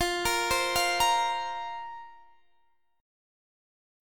Fsus4 Chord (page 2)
Listen to Fsus4 strummed